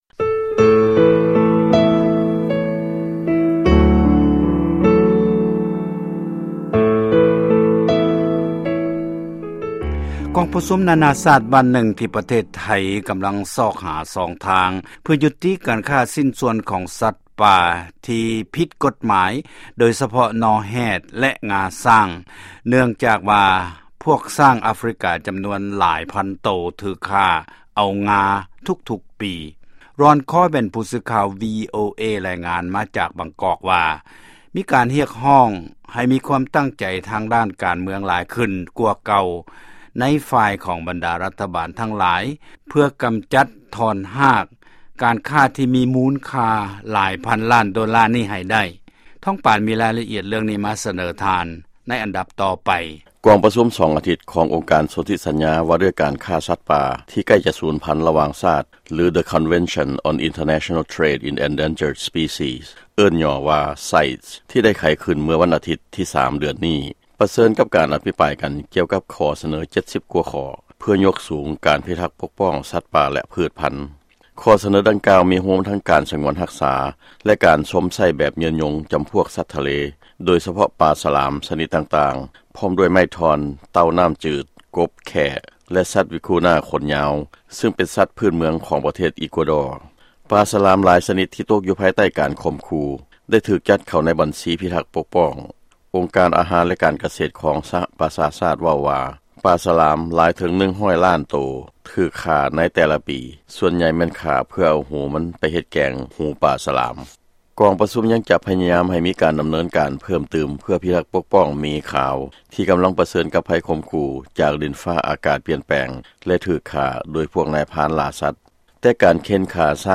ຟັງລາຍງານ ກອງປະຊຸມເລຶ່ອງສັດປ່າ